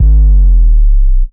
G#_07_Sub_02_SP.wav